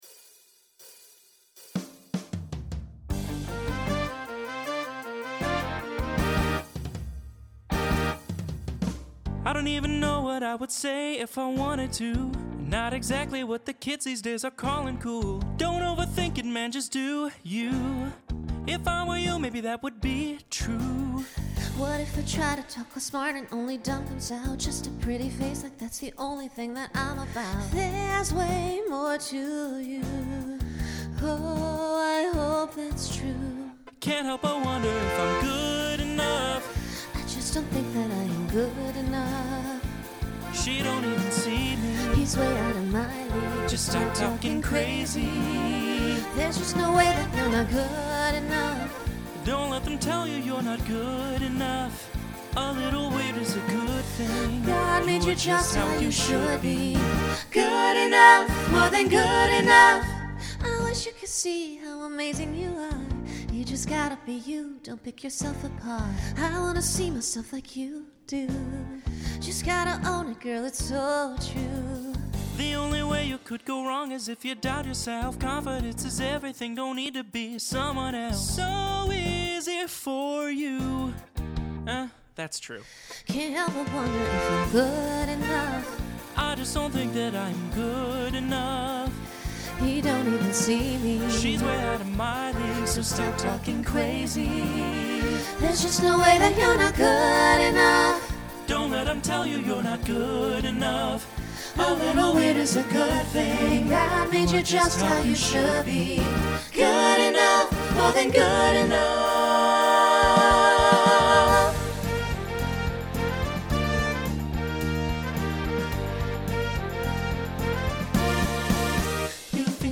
Broadway/Film , Pop/Dance
Voicing SATB